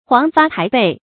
黃發駘背 注音： ㄏㄨㄤˊ ㄈㄚˋ ㄉㄞˋ ㄅㄟˋ 讀音讀法： 意思解釋： 見「黃發臺背」。